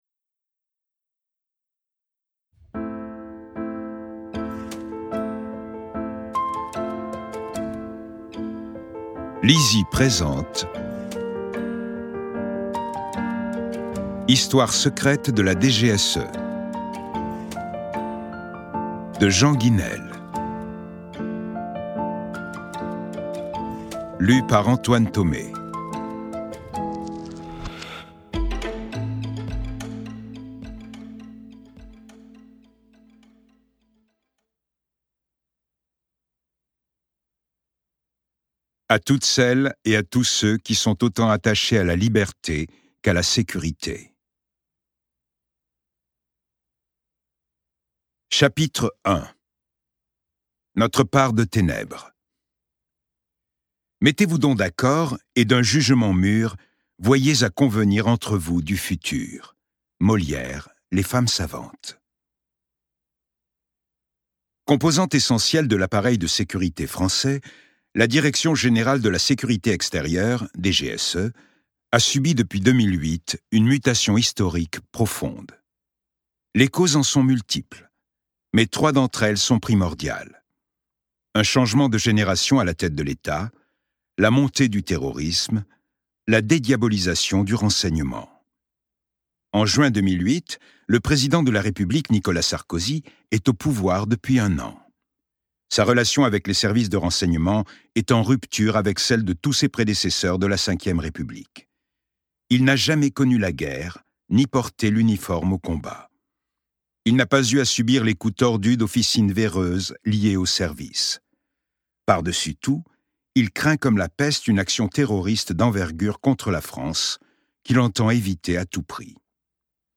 je découvre un extrait - Histoire secrète de la DGSE de Jean Guisnel